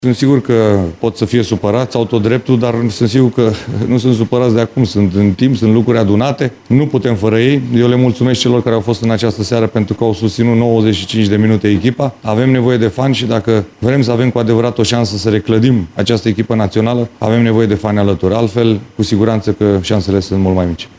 La finalul meciului de la Zenica, suporterii români prezenți la meci i-au apostrofat pe jucătorii naționalei. Edward Iordănescu e de părere că supărarea fanilor vine după șirul de eșecuri din ultimii ani și subliniază că echipa are nevoie de susținere pentru a se reclădi: